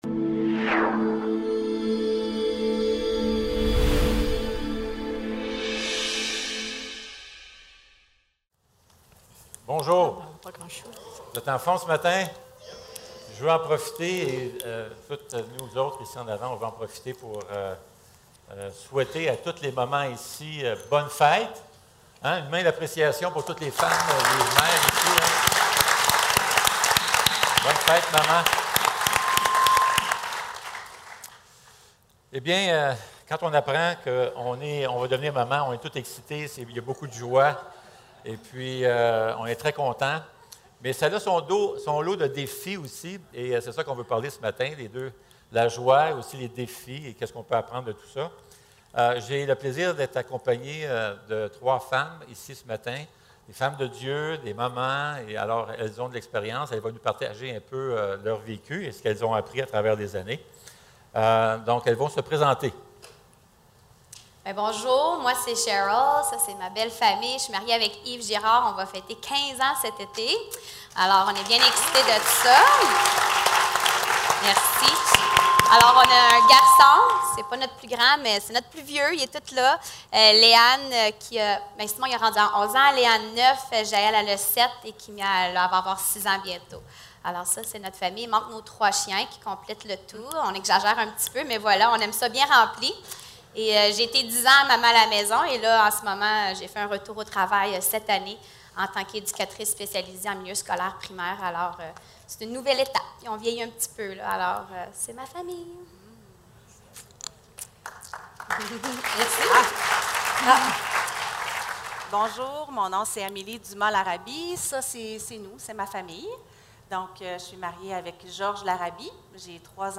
Fête des mères - Panel de mamans < église le Sentier | Jésus t'aime!